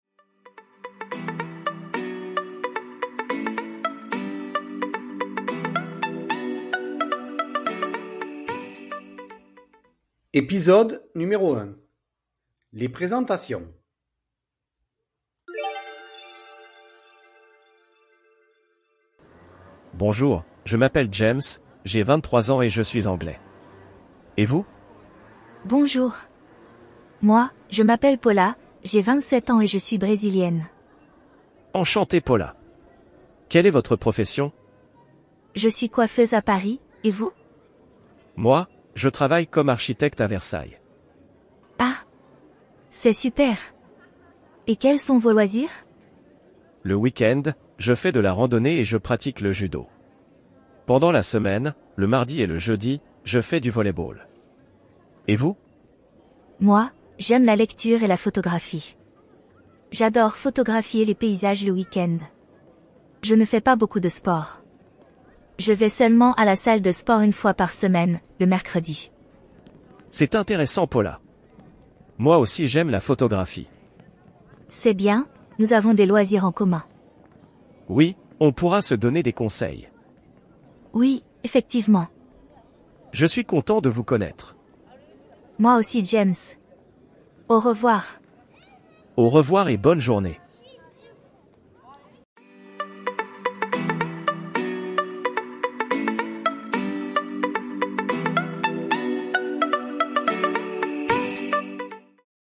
Dialogue: